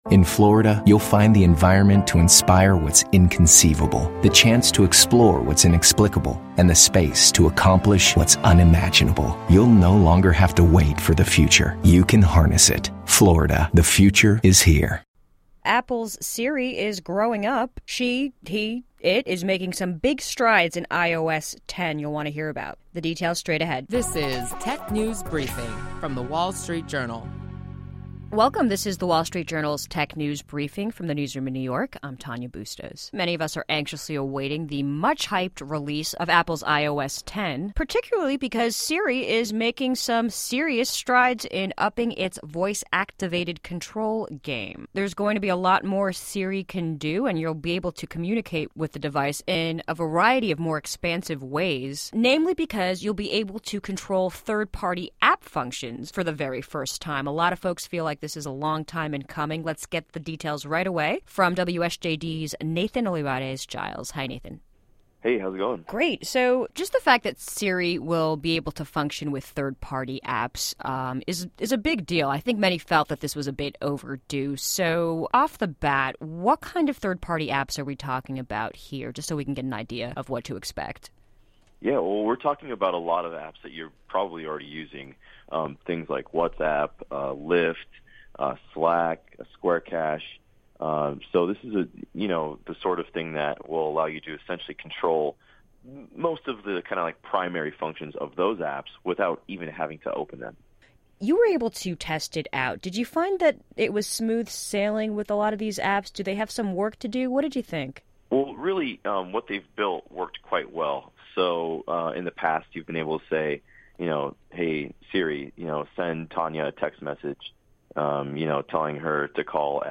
Stay informed on the latest technology trends with daily insights on what’s hot and happening in the world of technology. Listen to our WSJD reporters discuss notable tech company news, new tech gadgets, personal technology updates, app features, start-up highlights and more.